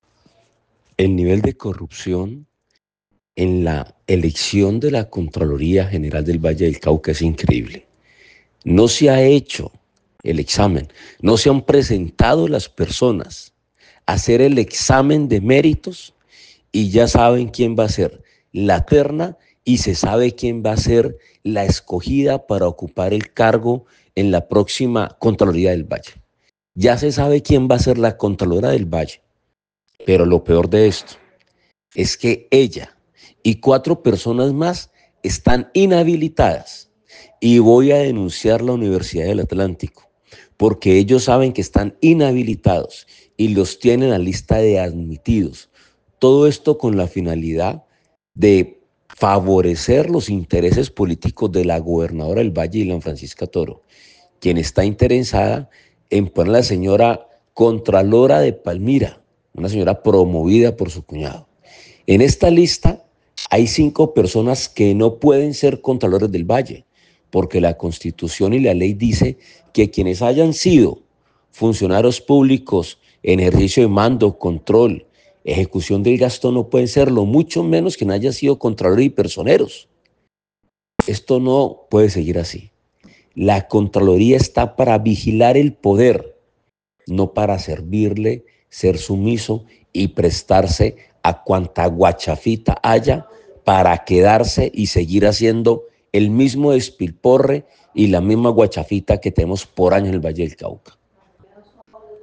Voz: Alejandro Ocampo